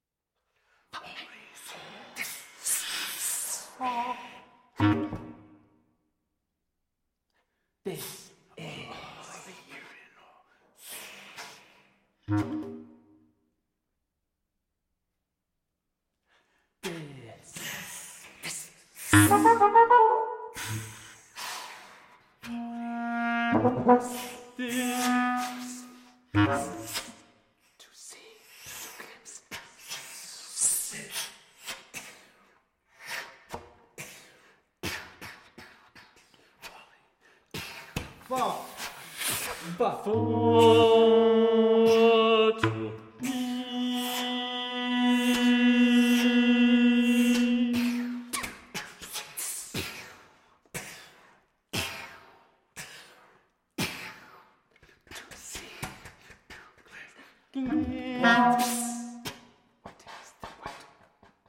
• Genres: Classical
baritone voice, bass clarinet, trombone, trumpet